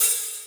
open hi hat 1.wav